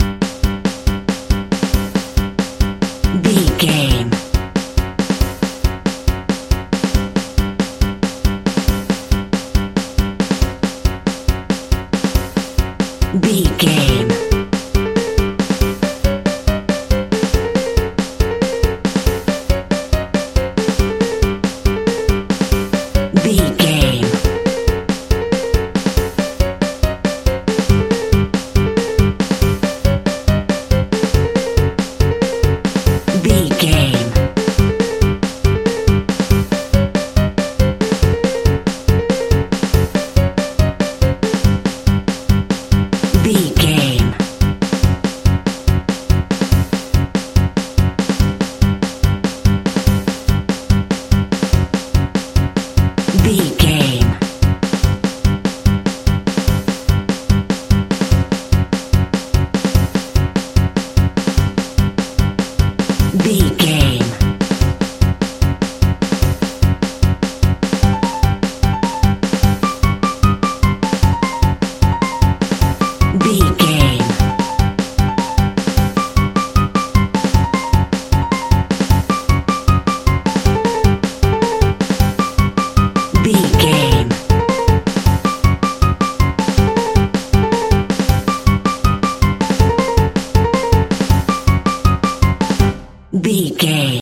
Aeolian/Minor
Fast
drums
bass guitar
electric guitar
piano
hammond organ
childrens music